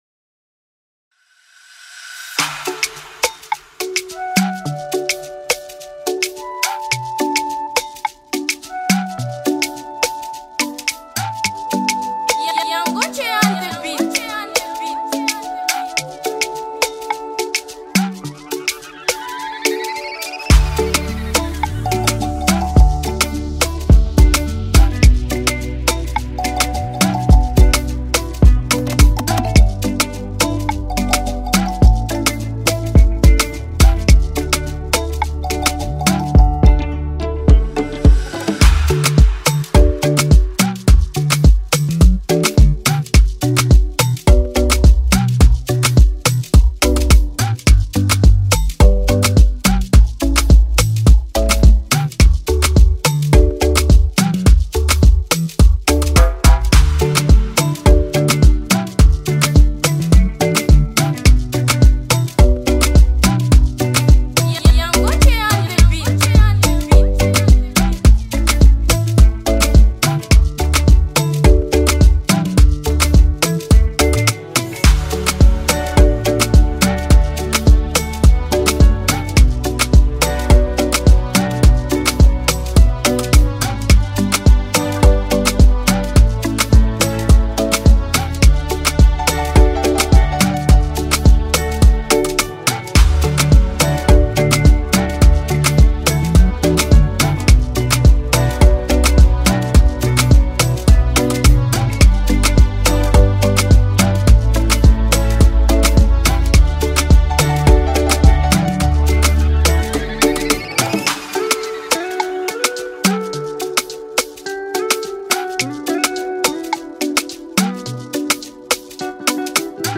instrumental Afro Pop Free beat
December 27, 2019 admin Instrumentals 0